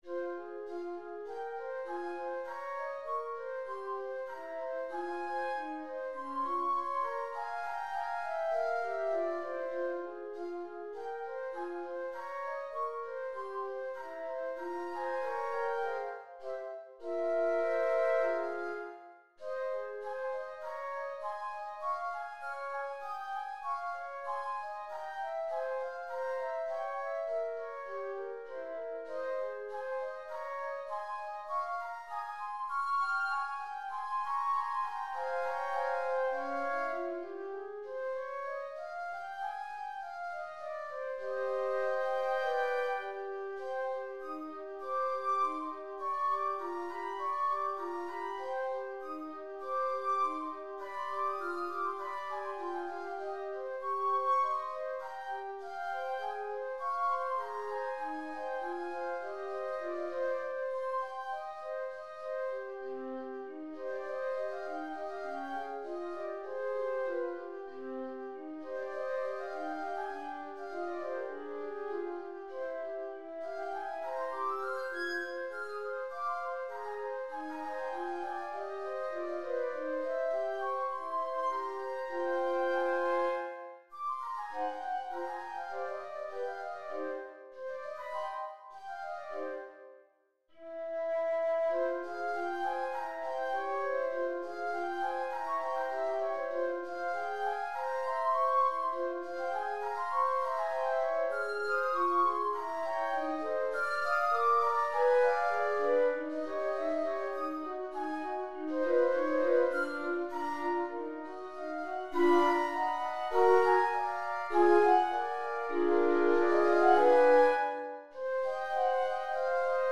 Flute Trio for the Month of Tranquility (2014) | Works | Robert Martin, composer